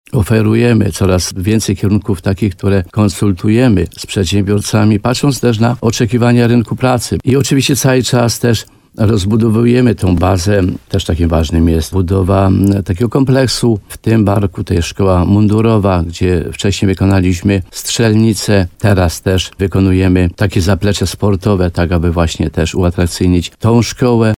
Jak mówił starosta limanowski Mieczysław Uryga w programie Słowo za Słowo na antenie RDN Nowy Sącz, ważny punkt na ponad 50 milionów złotych dotyczy z kolei inwestycji.